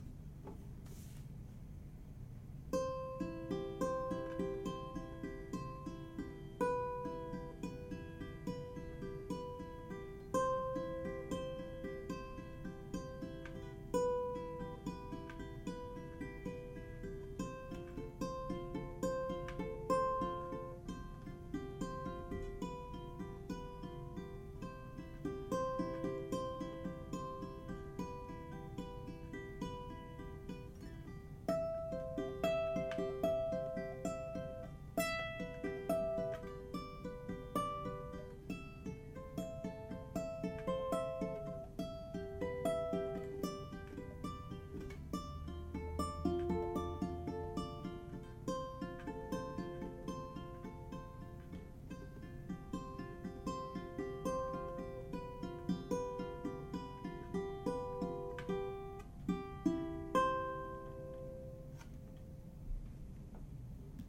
Ukulele aNueNue Moon Bird UT200
Size / Scale Tenor
Body Material Spruce Top + Indian Rosewood
Strings: Uke Logic Soft High G (Pink)